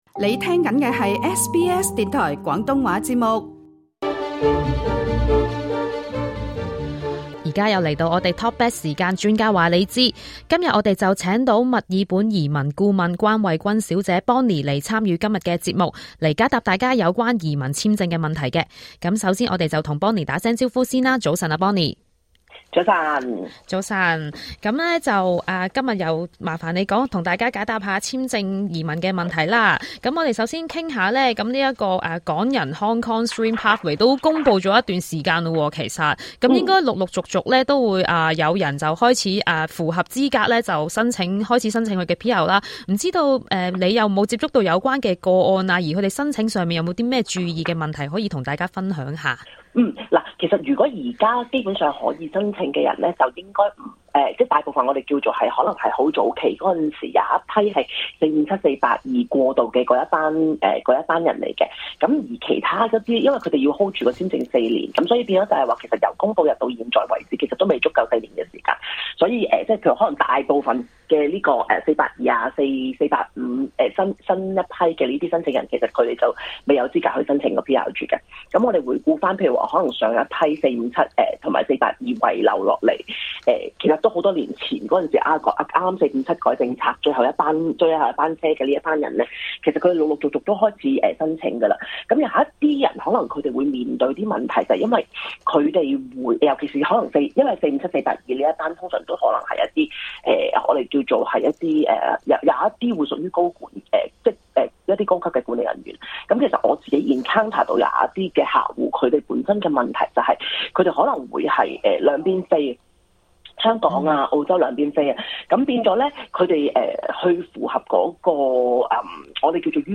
另外，她還解答不少聽眾的問題，包括考公民試的次數，申請護照的事情、及父母移民簽證等。